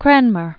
Cran·mer
(krănmər), Thomas 1489-1556.